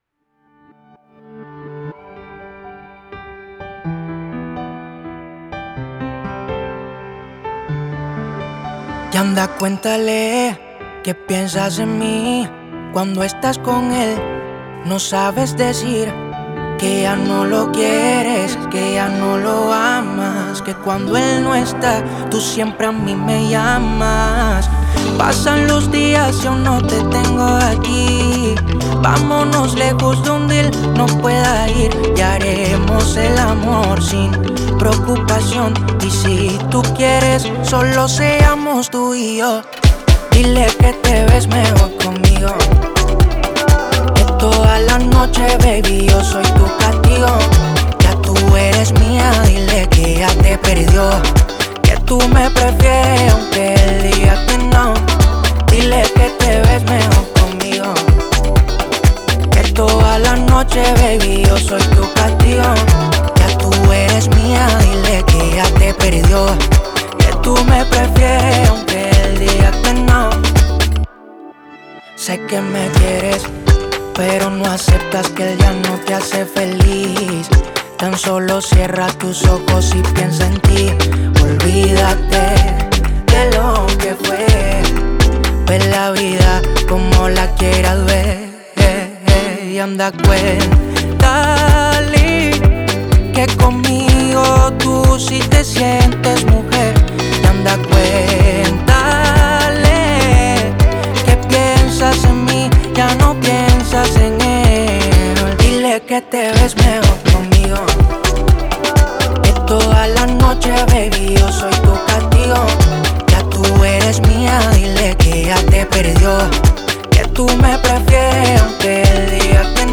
Pop fusión